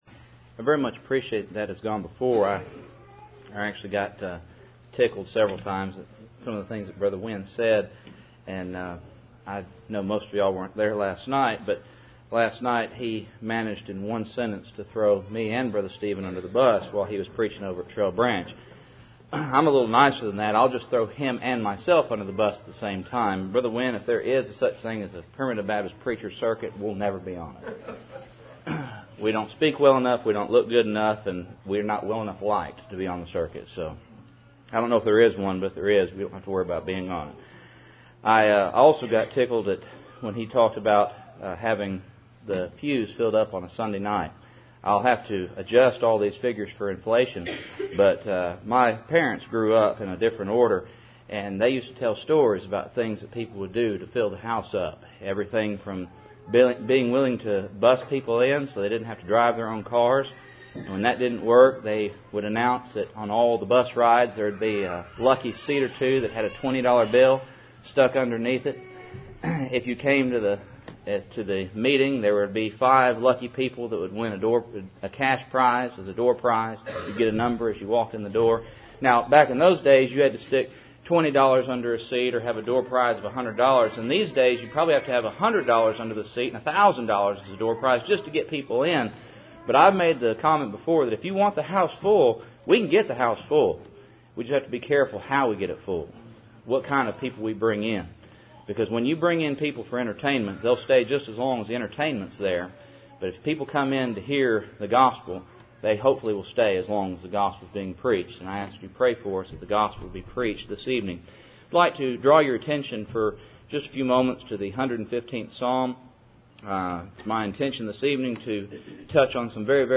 Psalm 115:0 Service Type: Cool Springs PBC Sunday Evening %todo_render% « Simon & A Woman